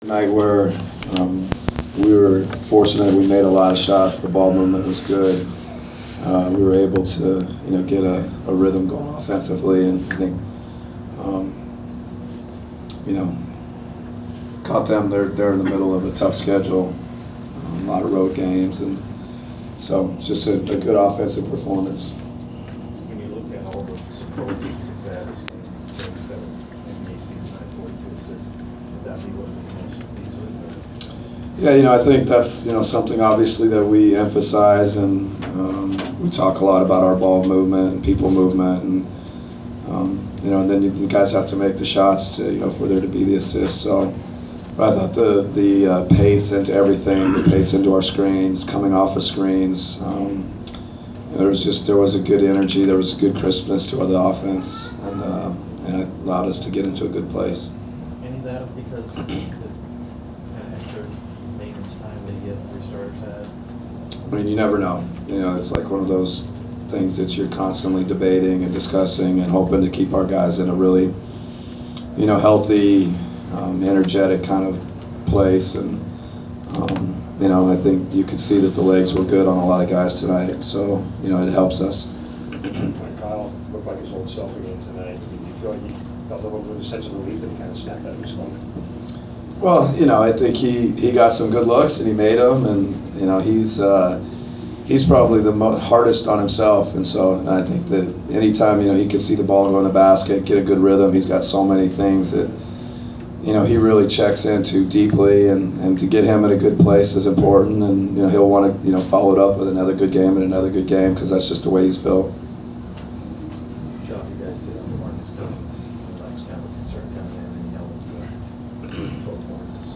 Inside the Inquirer: Postgame presser with Atlanta Hawks’ head coach Mike Budenholzer (3/9/15)
We attended the postgame presser of Atlanta Hawks’ head coach Mike Budenholzer following his team’s 130-105 home win over the Sacramento Kings on Mar. 9. Topics including the team’s record-setting performance, limiting Kings’ center DeMarcus Cousins and outlook for upcoming road trip.